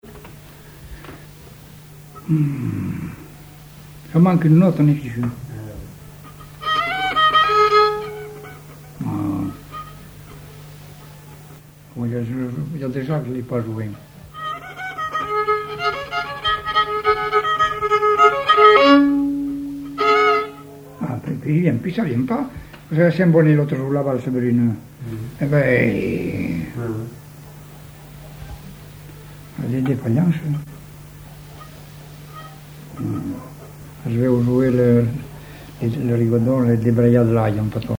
Rigodon au violon La débraillée
violoneux, violon,
musique traditionnelle